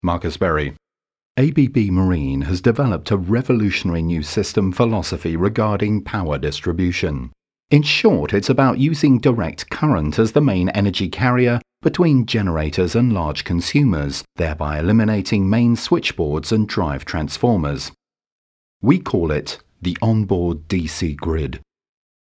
a warm, neutral and versatile British voice
Sprechprobe: Industrie (Muttersprache):
a neutral UK voice